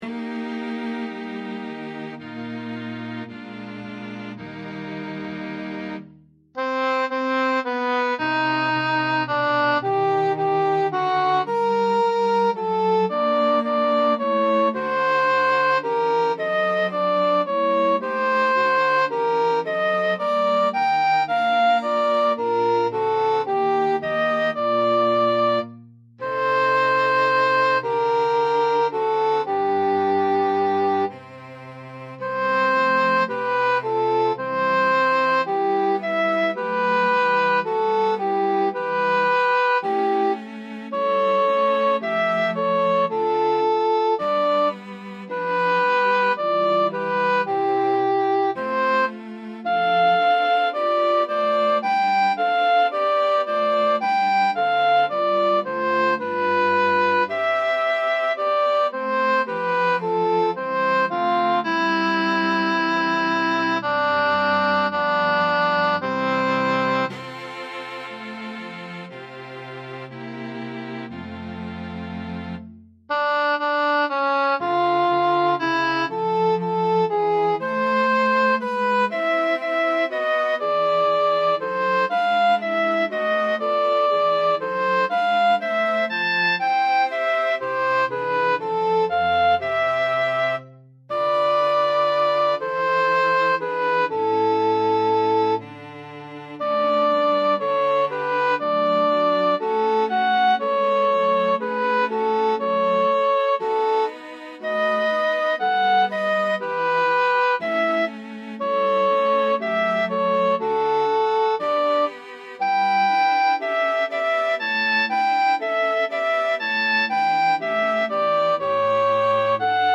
Erst ein Ton tiefer, dann Wiederholung original